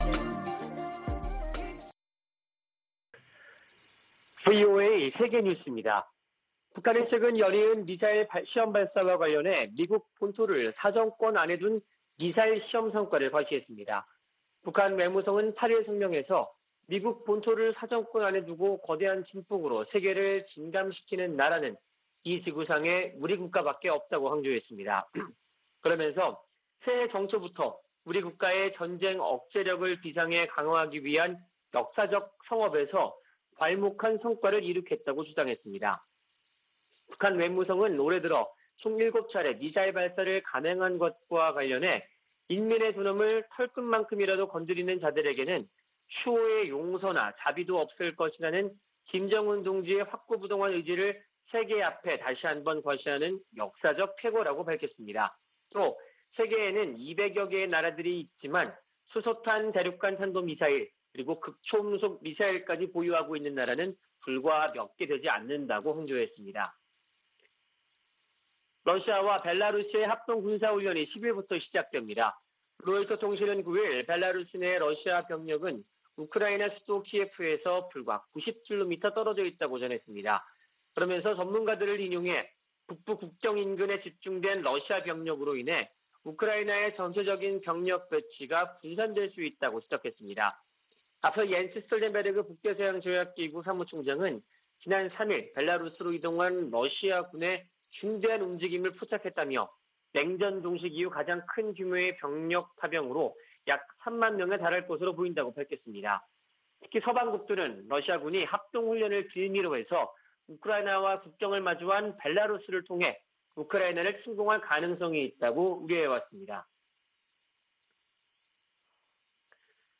VOA 한국어 아침 뉴스 프로그램 '워싱턴 뉴스 광장' 2021년 2월 10일 방송입니다. 미 국무부는 대북 인도주의 지원을 위한 '제재 면제' 체제가 가동 중이라며, 제재가 민생을 어렵게 한다는 중국 주장을 반박했습니다. 유엔이 지원 품목을 제재 면제로 지정해도 북한의 호응을 얻지 못한 채 속속 기간 만료되고 있는 것으로 나타났습니다. 북한 대륙간탄도미사일(ICBM) 기지 완공이 처음 확인됐다고 미 전략국제문제연구소(CSIS)가 밝혔습니다.